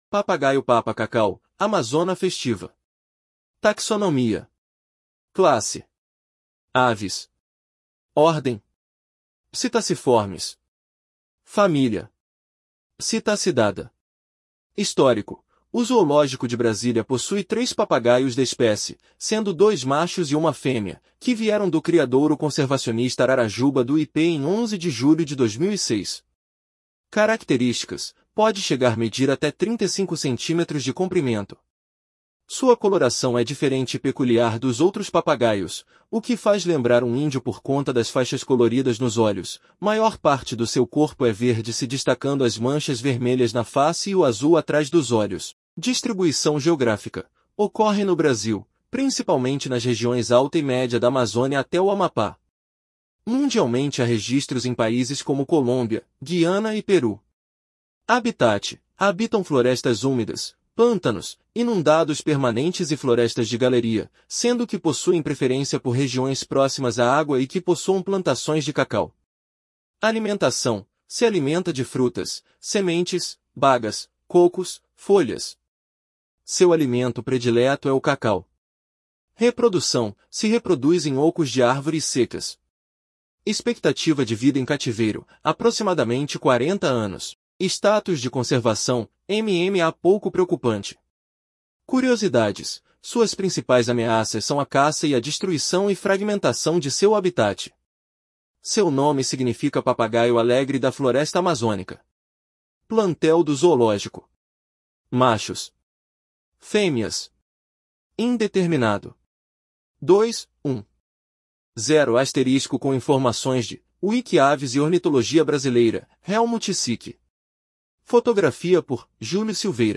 Papagaio-papa-cacau (Amazona festiva)